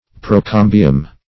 Search Result for " procambium" : The Collaborative International Dictionary of English v.0.48: Procambium \Pro*cam"bi*um\, n. [NL.